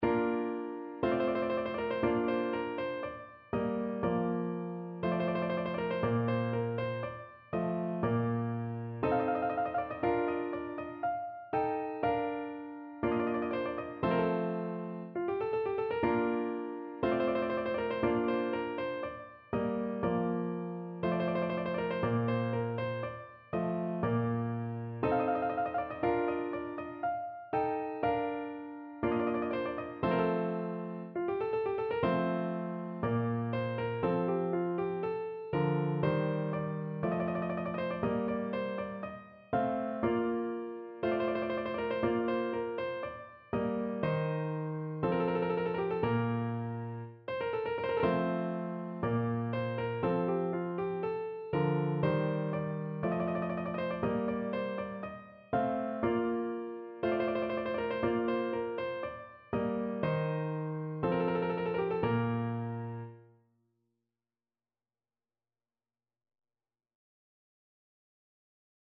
No parts available for this pieces as it is for solo piano.
Bb major (Sounding Pitch) (View more Bb major Music for Piano )
= 60 Aria
4/4 (View more 4/4 Music)
Piano  (View more Easy Piano Music)
Classical (View more Classical Piano Music)